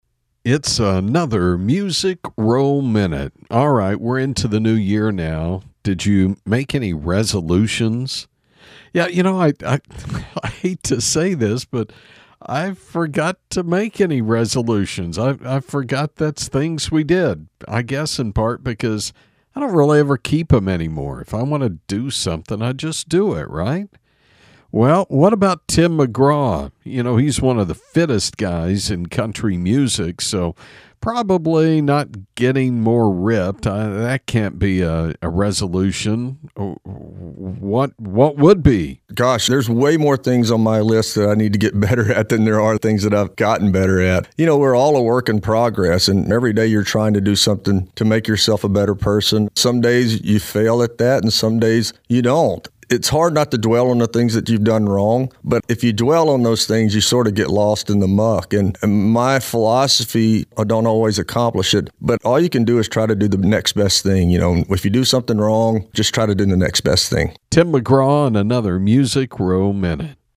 Music Row Minute is a daily radio feature on 106.1FM KFLP